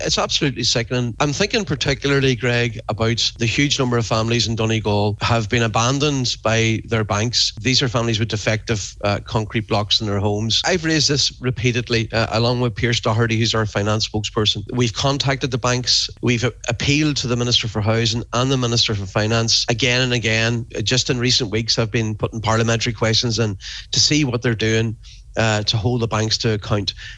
Speaking on today’s Nine ’til Noon show, he described it as sickening: